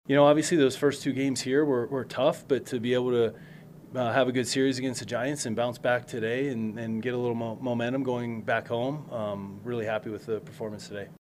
Manager Don Kelly talked about the weekend, which was a wild one at Coors Field.